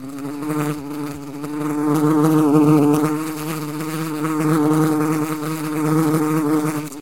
flies2.ogg.mp3